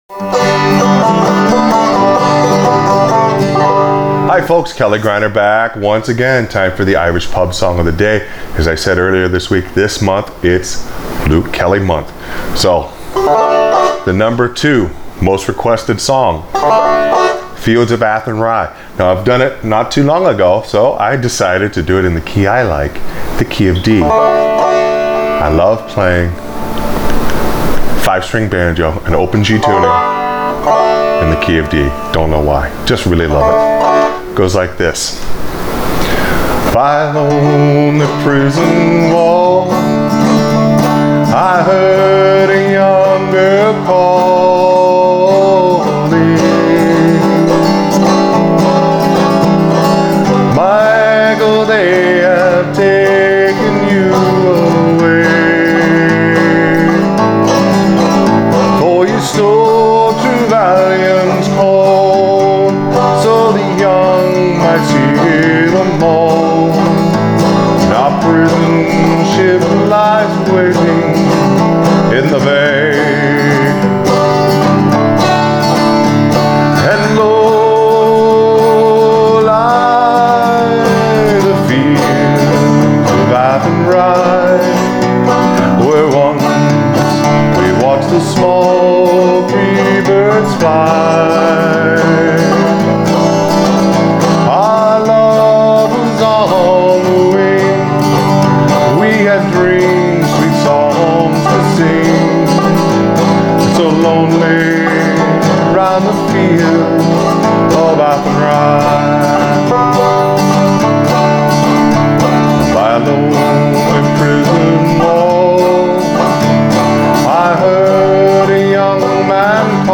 Accompaniment for Frailing Banjo